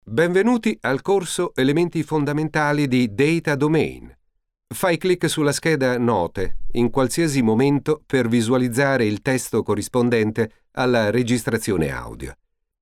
Natural clear and pleasing voice
Sprechprobe: eLearning (Muttersprache):